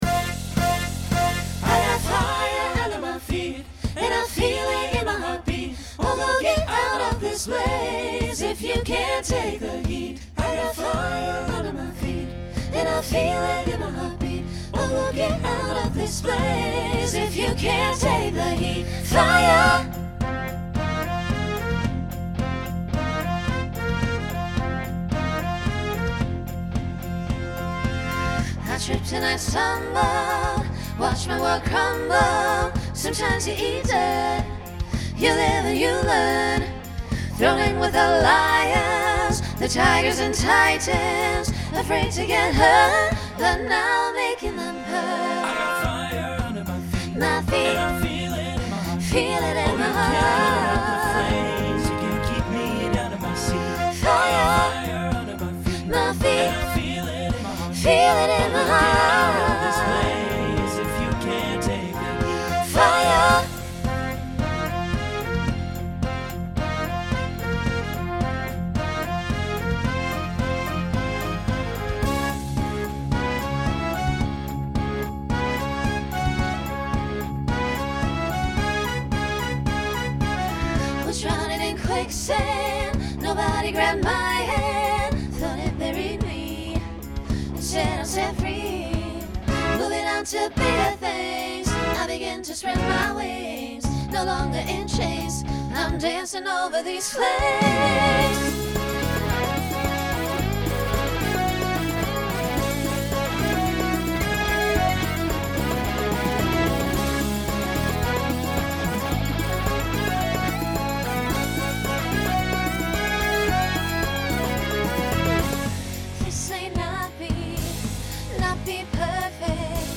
Genre Pop/Dance , Rock
Transition Voicing Mixed